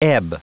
Prononciation du mot ebb en anglais (fichier audio)
Prononciation du mot : ebb